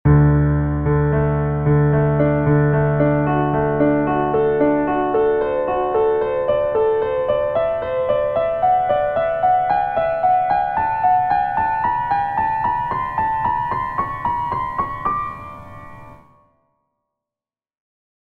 泛音列的各个音可以组成一个整体，它们听上去不是歪的。